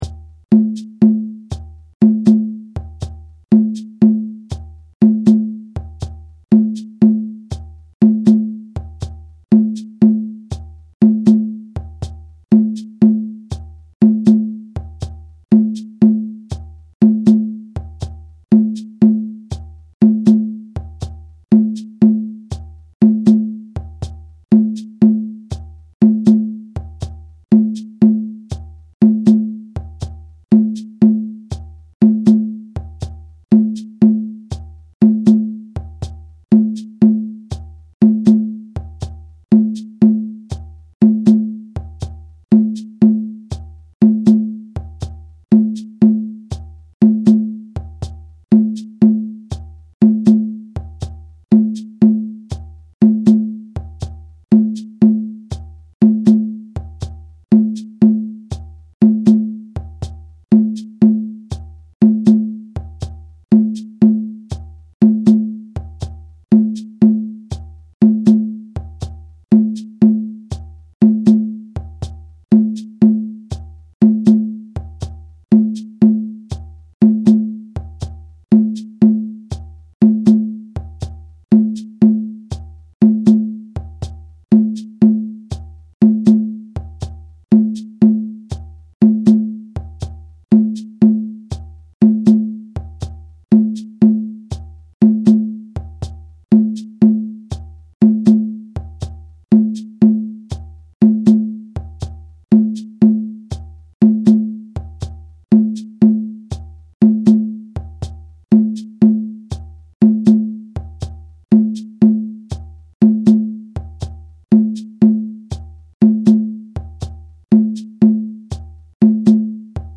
• Play-Along Audio (available in 4 speeds)
audio (with shekeré)